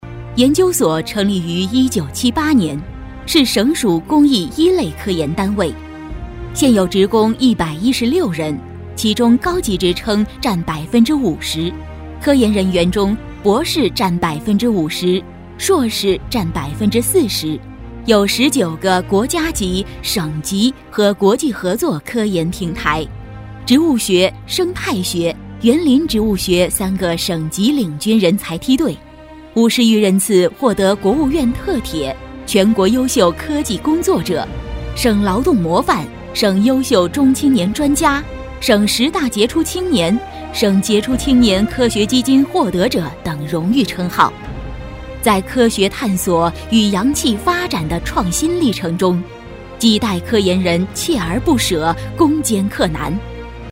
激情力度 企业专题,人物专题,医疗专题,学校专题,产品解说,警示教育,规划总结配音
大气稳重女音，年轻活力。